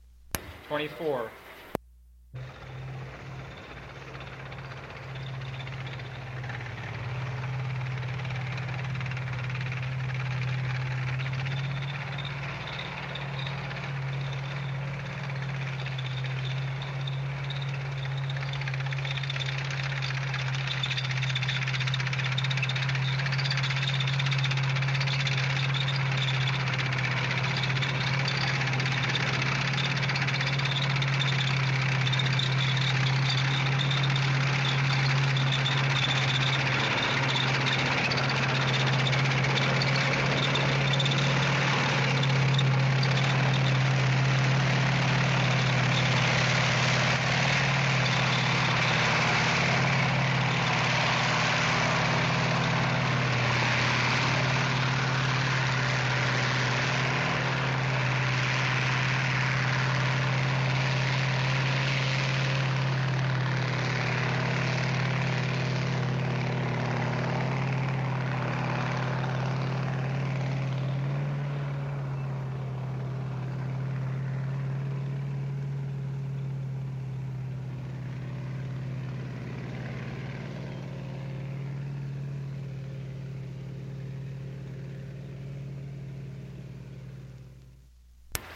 老式汽车和船只喇叭 " G3710法国汽车喇叭
描述：法国汽车喇叭。短暂的高音突发没有共鸣。 这些是20世纪30年代和20世纪30年代原始硝酸盐光学好莱坞声音效果的高质量副本。
我已将它们数字化以便保存，但它们尚未恢复并且有一些噪音。
标签： 牛角 交通 复古
声道立体声